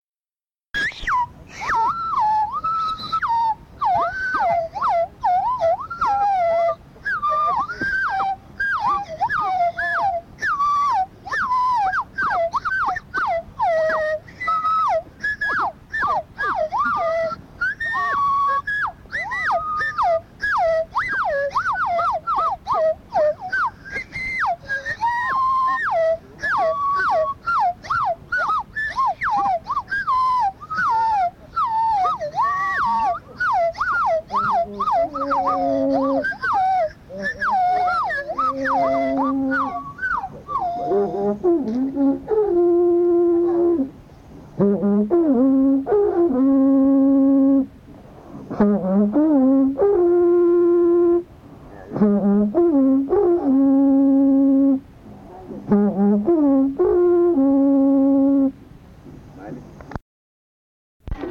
Instrumental (sonidos)
Arara, Amazonas (Colombia)
presentan un instrumental con pitos, sonido aerófono, trompeta y tambores
La grabación fue realizada durante una fiesta de Pelazón.
and other anonymous performers present an instrumental piece featuring whistles (pitos), aerophone sounds, trumpet, and drums, representing the entrance to the Pelazón festival when the girl is in the corral. The chief horn is called “tokü” and the smaller one “iburi.” The recording was made during a Pelazón festival.